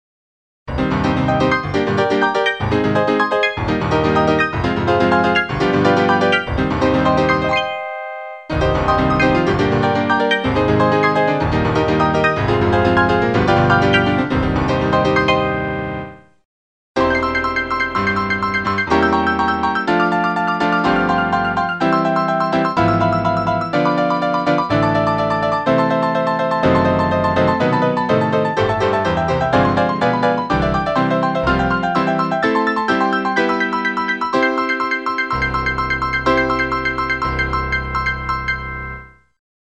First, it plays a large number of staccato notes all the way across the keyboard.
Next, the same notes that were played in the first section are played again, but with longer note durations.
The last portion of the test plays chords in the middle register while playing the same staccato notes in the treble register that are two octaves higher.